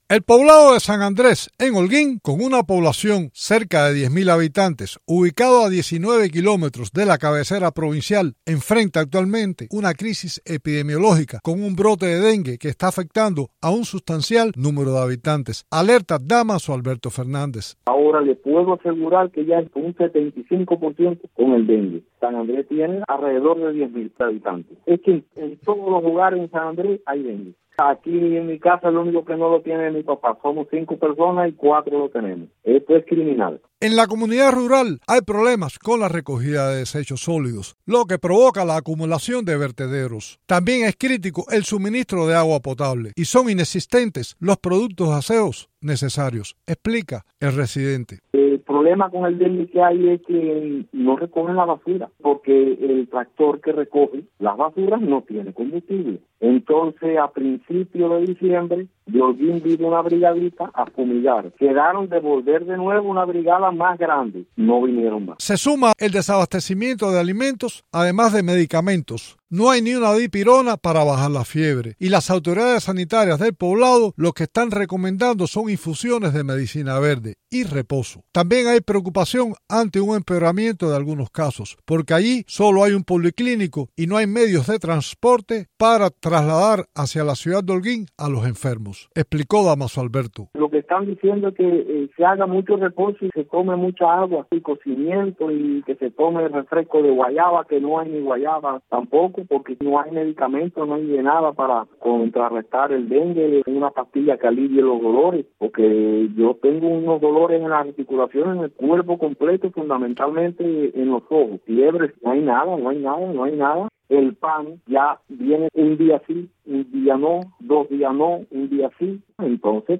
Habitantes del poblado de San Andrés alertan sobre brote de dengue